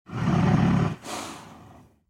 Horse Snort Bouton sonore